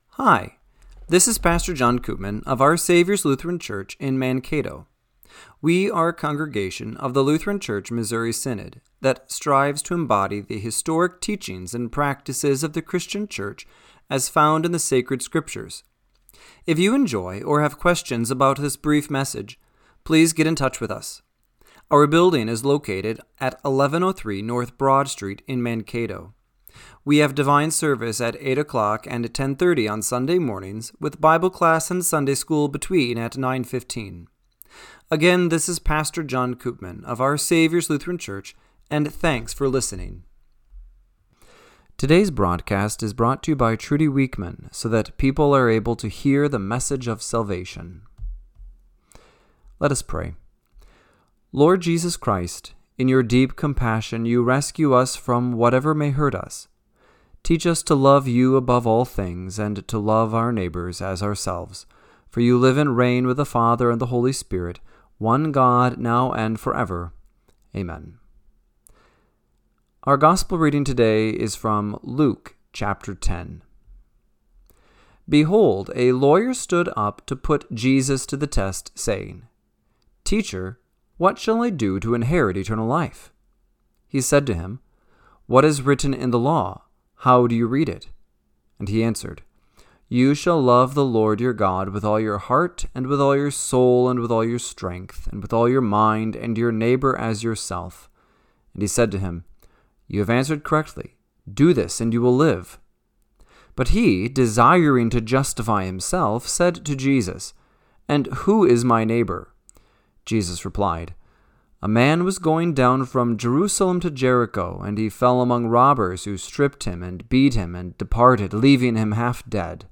Radio-Matins-7-13-25.mp3